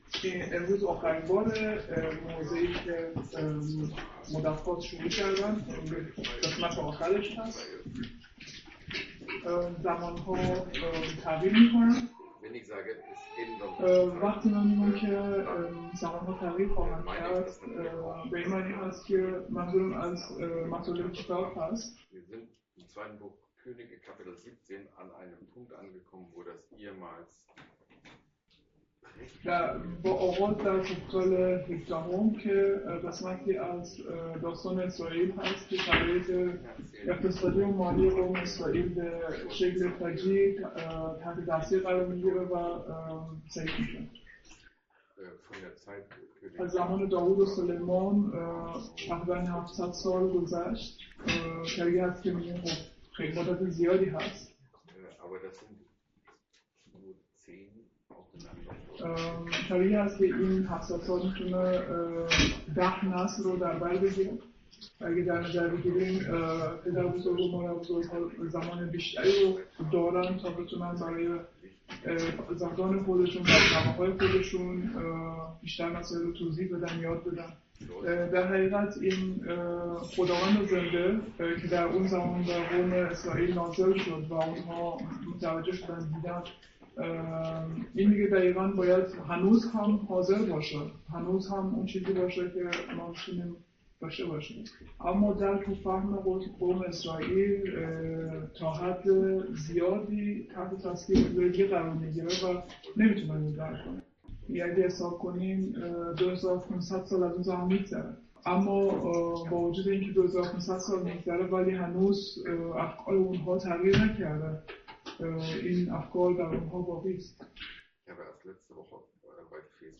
Könige 17,23-41 | Übersetzung in Farsi